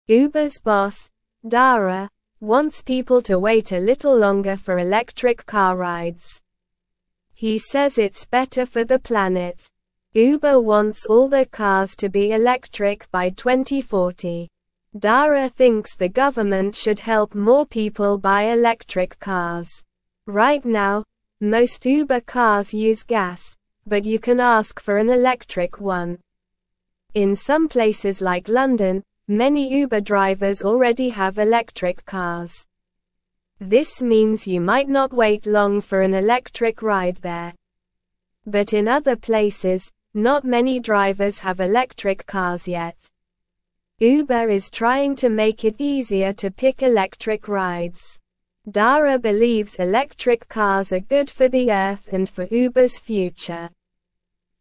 Slow
English-Newsroom-Beginner-SLOW-Reading-Uber-Boss-Wants-More-Electric-Cars-for-Rides.mp3